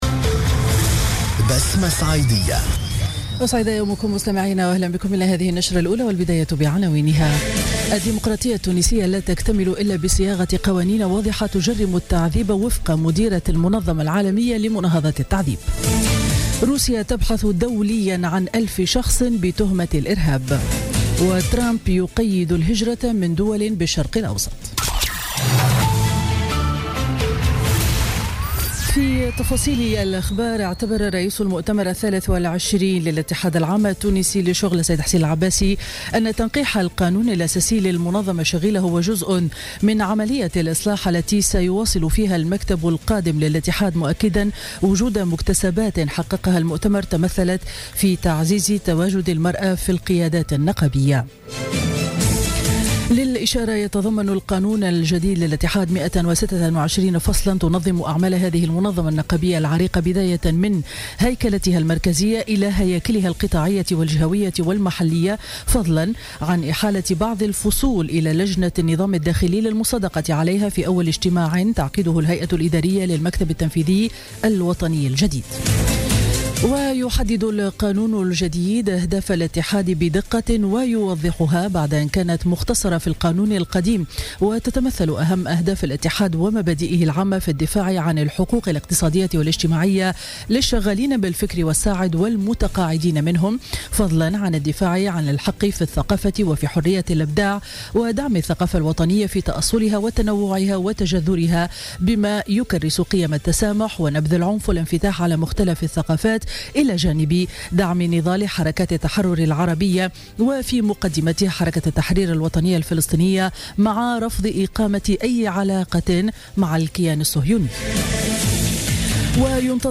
نشرة أخبار السابعة صباحا ليوم الأربعاء 25 جانفي 2017